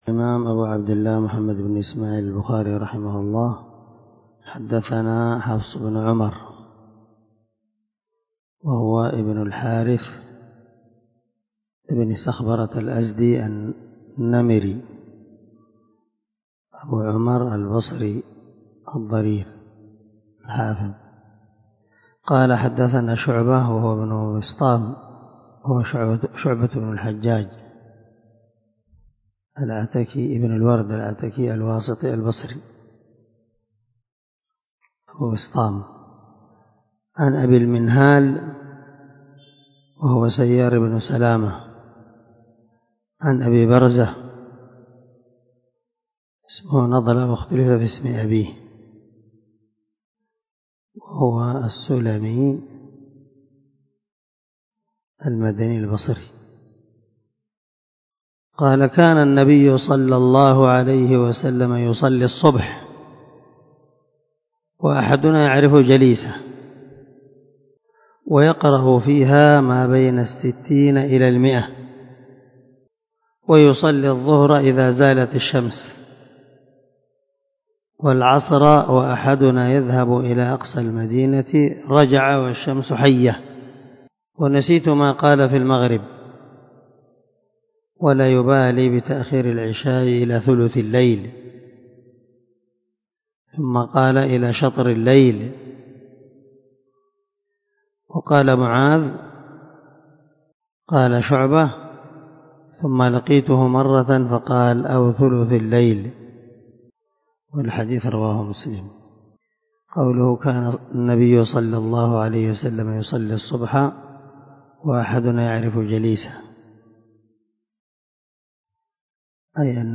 عنوان الدرس:
✒ دار الحديث- المَحاوِلة- الصبيحة.